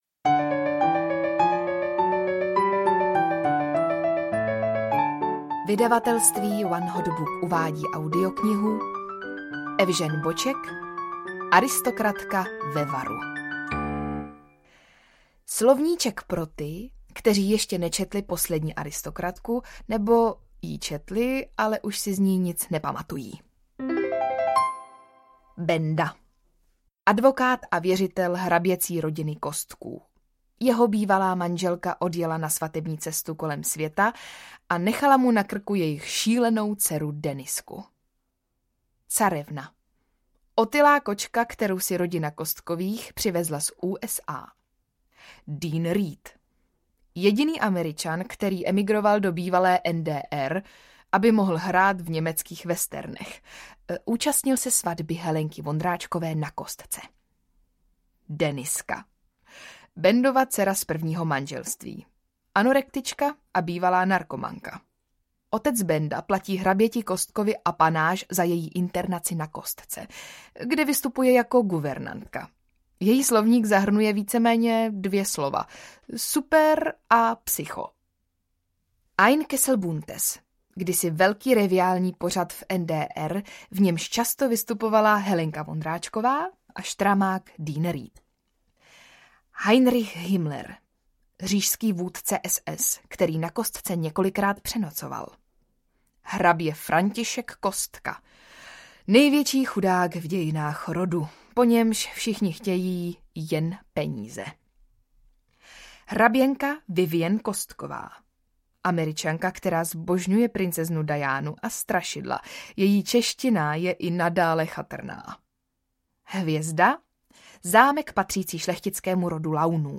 Aristokratka ve varu audiokniha
Ukázka z knihy
Audio verzi pokračování nejúspěšnější humoristické knihy roku 2012 oceněné Cenou Miloslava Švandrlíka vypráví opět Veronika Kubařová.
• InterpretVeronika Khek Kubařová